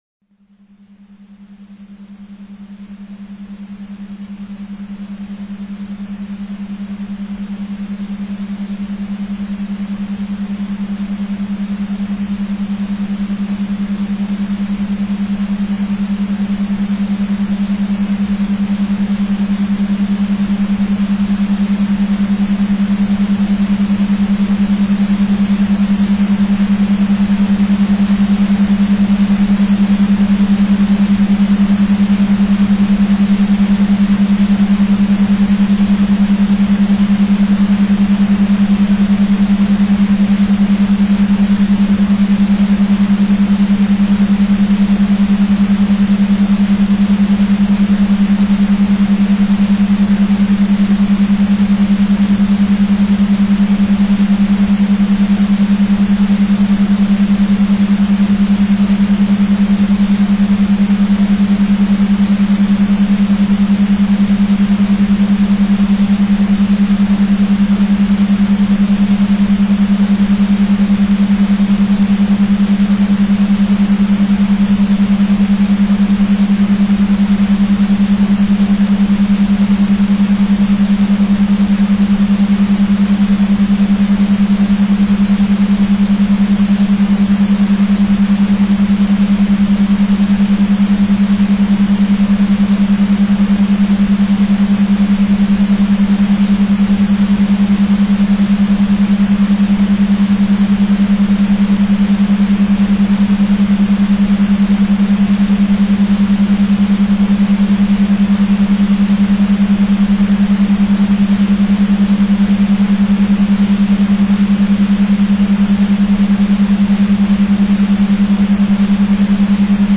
Цифровой наркотик (аудио наркотик) Морфий